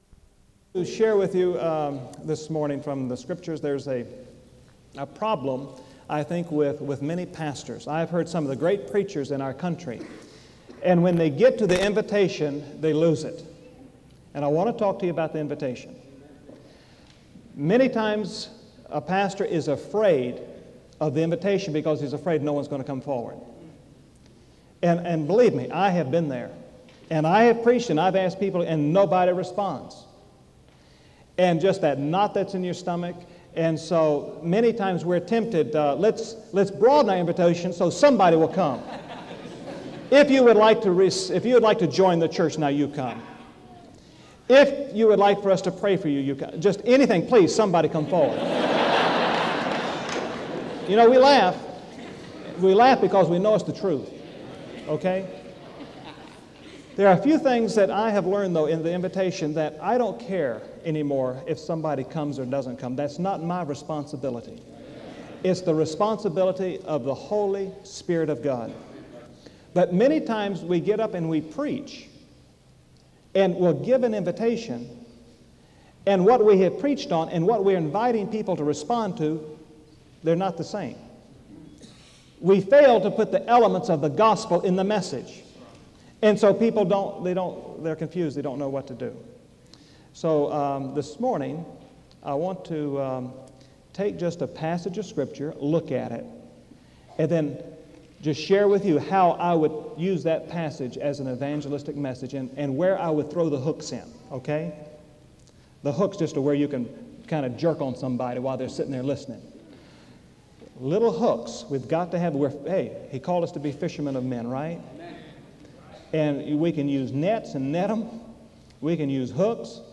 SEBTS Chapel - Franklin Graham February 3, 1999
En Collection: SEBTS Chapel and Special Event Recordings SEBTS Chapel and Special Event Recordings - 1990s Miniatura Título Fecha de subida Visibilidad Acciones SEBTS_Chapel_Franklin_Graham_1999-02-03.wav 2026-02-12 Descargar